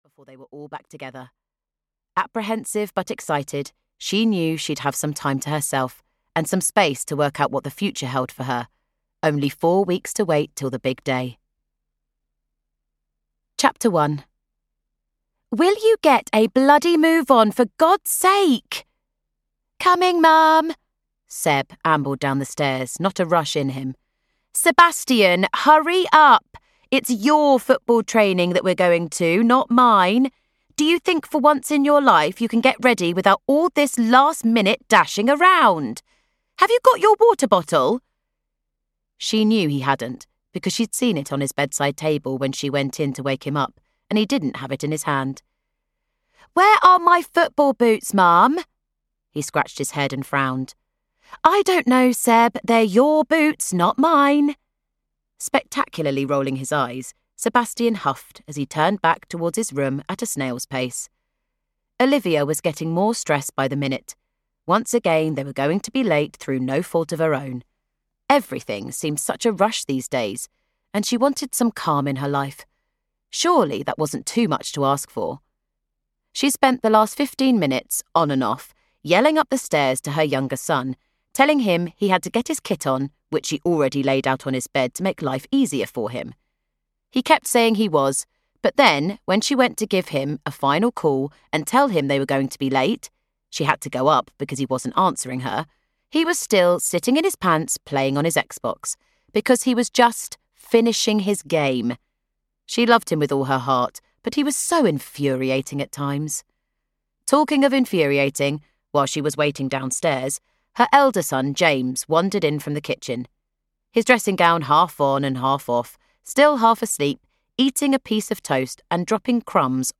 Sunshine and Second Chances (EN) audiokniha
Ukázka z knihy